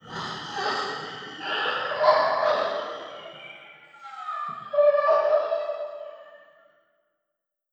effect__ghost_reveal.wav